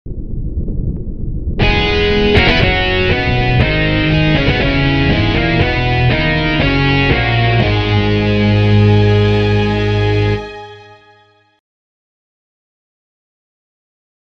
>>1001 DRY Anon, here's a WIP for the fountain-sealing theme.
>>1012 sounds a bit muffled for some reason? though good, I like it